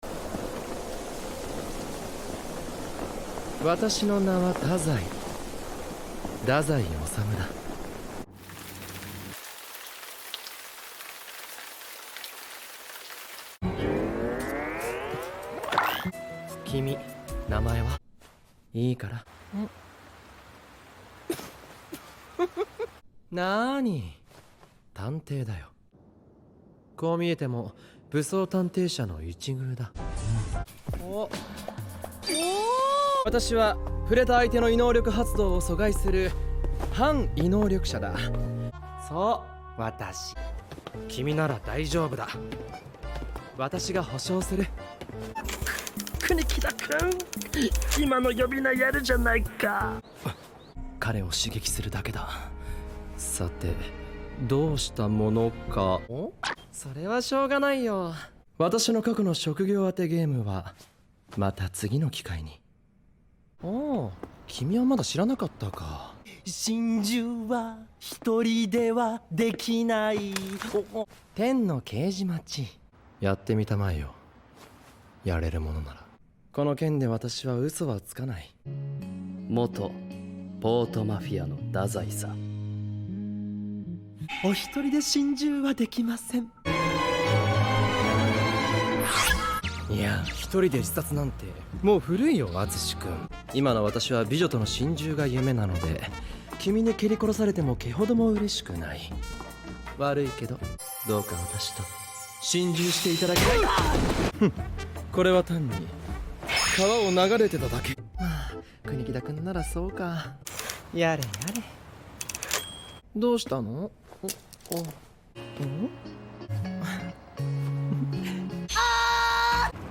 DAZAI soft scenes || Bungou Stray Dogs ASMR (256  kbps).mp3